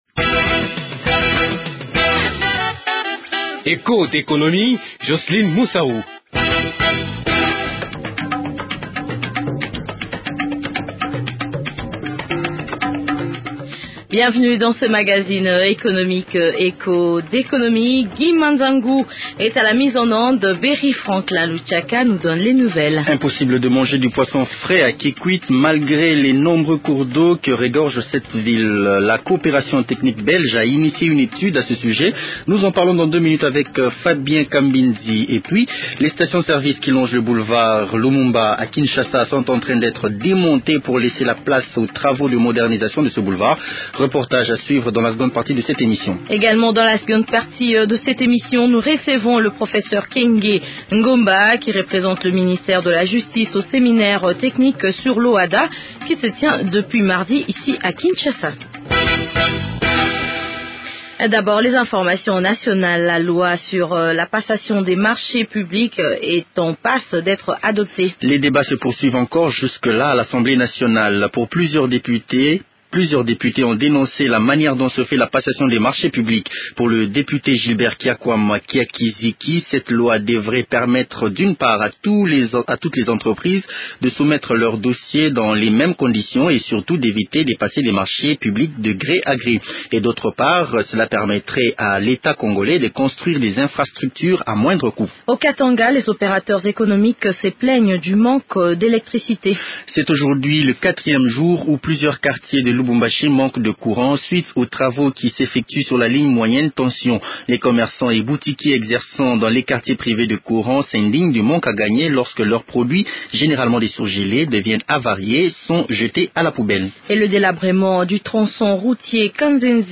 Cela pour laisser place aux travaux de modernisation de ce boulevard, reportage à suivre dans cette émission.